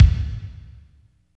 31.01 KICK.wav